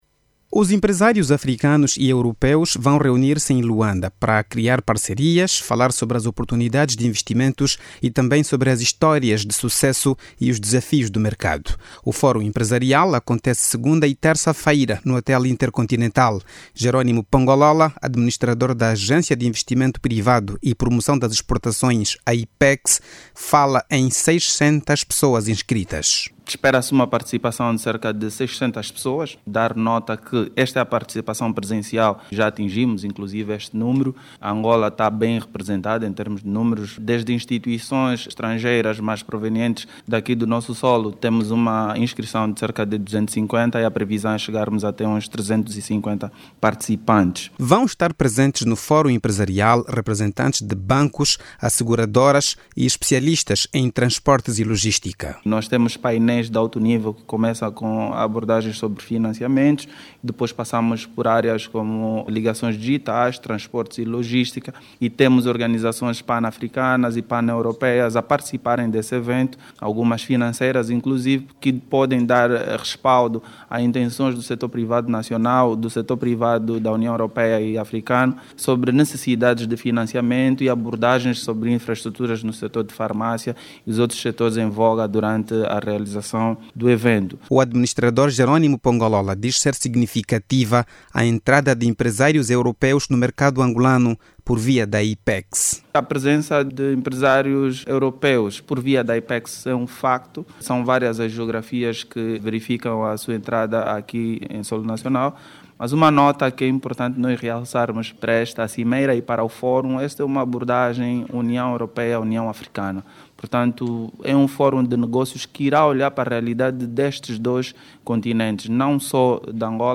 Mais de 600 empresários entre europeus e africanos, são esperados em Luanda, para a realização do fórum empresaria a decorrer nos dias 24 e 25 deste mês. Os homens de negócios, pretendem elaborar uma plataforma comum, de actuação nos mercados dos dois continentes. Ouça no áudio abaixo toda informação com a reportagem